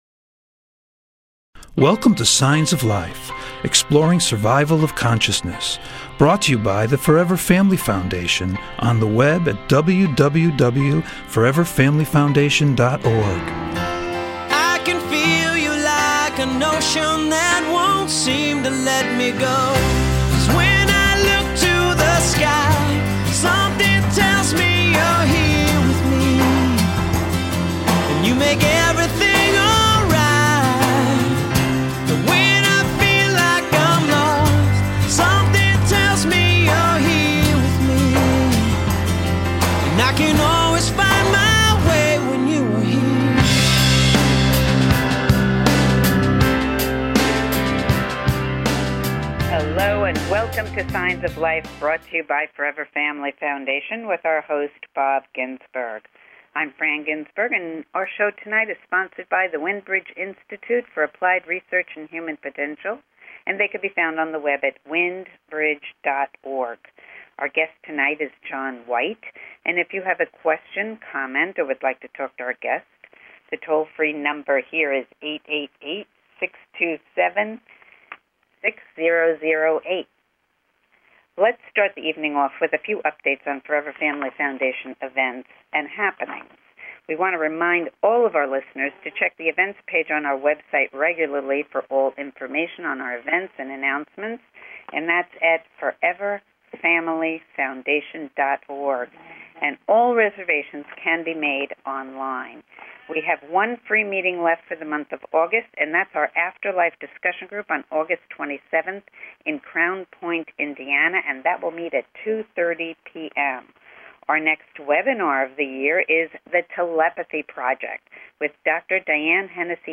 Signs of Life Radio Show is a unique radio show dedicated to the exploration of Life After Death!